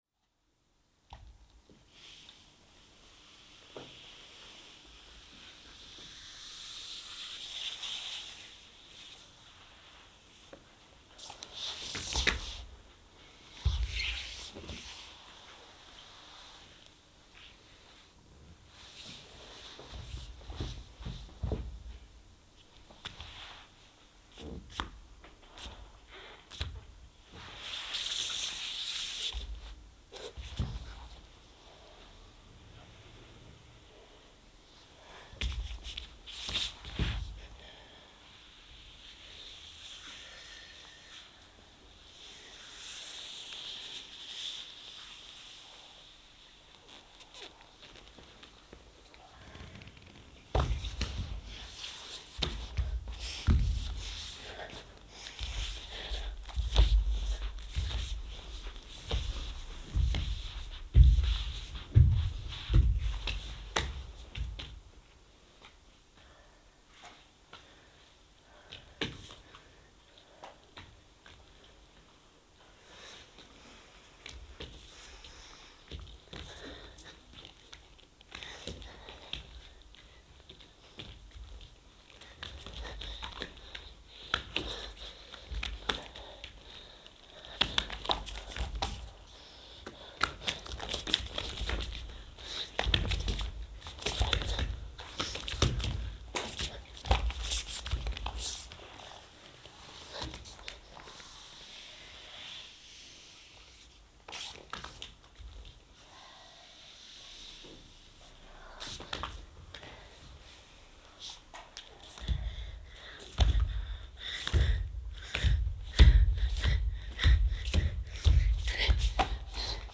electronics